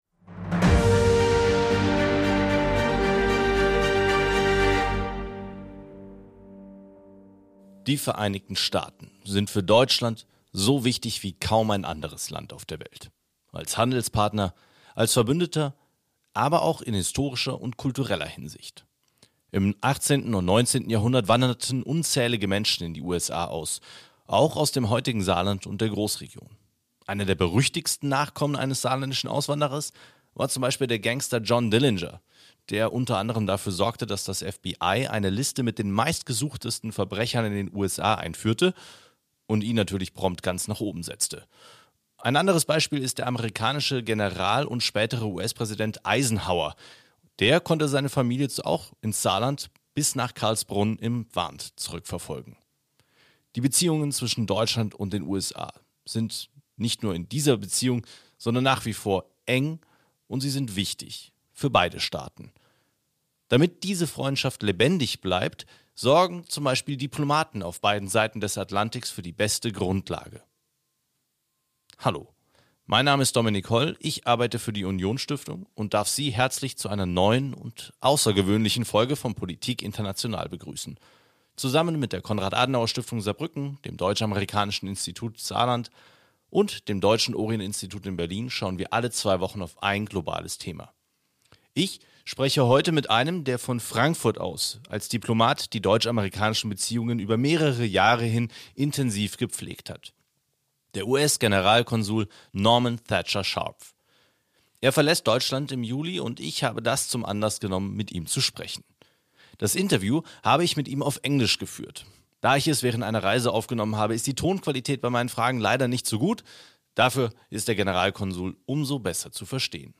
Eine besondere Episode - auf Englisch: Ich spreche mit Norman Thatcher Scharpf, dem scheidenden US-Generalkonsul in Frankfurt am Main. Wie er Diplomat geworden ist, was er als Offizier bei der Navy gelernt hat und wie er auf die deutsch-amerikansichen Beziehungen blickt.